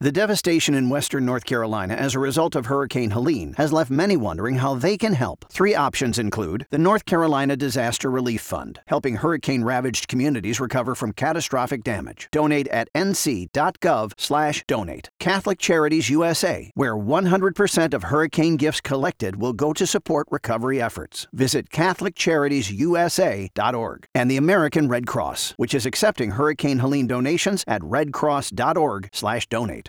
FREE PUBLIC SERVICE ANNOUNCEMENT FOR WESTERN NORTH CAROLINA FOLLOWING HURRICANE HELENE